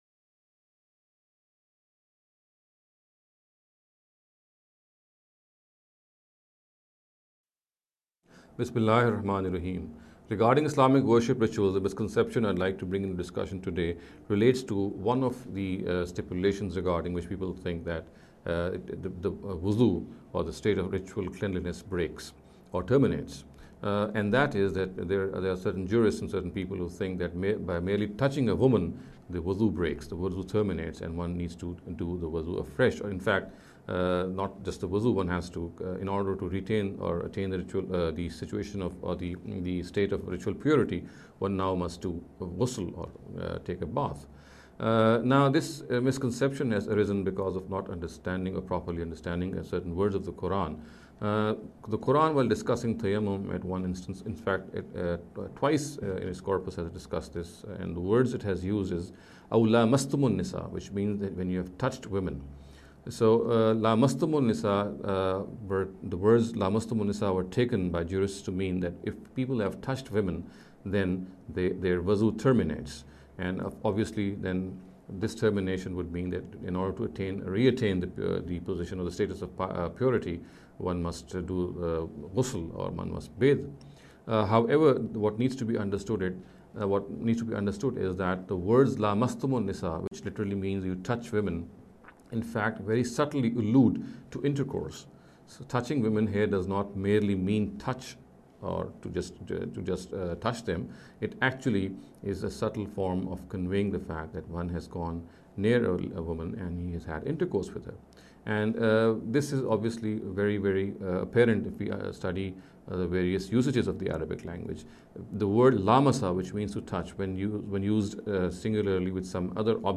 This lecture series will deal with some misconception regarding Islamic worship rituals.